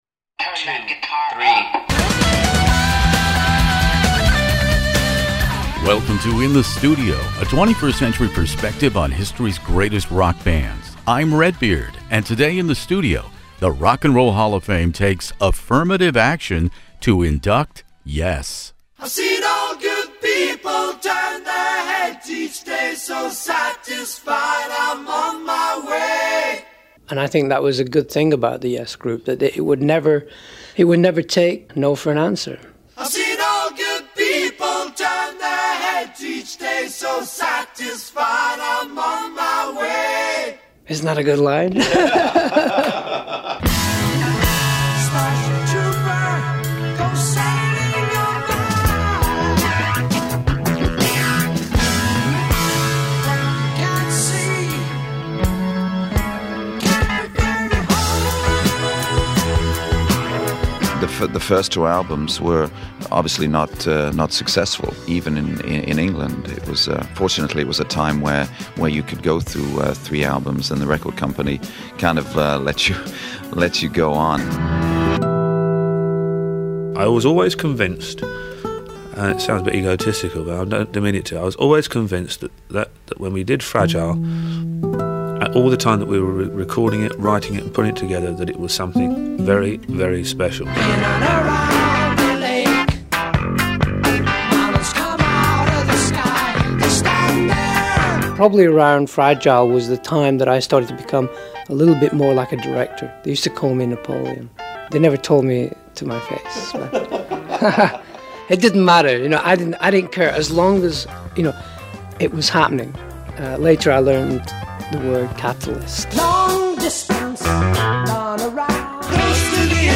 For part one of the best, In the Studio miraculously assembled everyone pictured below to share the saga, first focusing on the music and years of The YES Album , Fragile , and the #1 seller Close to the Edge from the detailed recollections of band co-founders Jon Anderson and the late Chris Squire, plus original and prodigal keyboard player Tony Kaye, original drummer Bill Bruford, guitarist Steve Howe, and rock keyboard pioneer Rick Wakeman all here in this classic rock interview.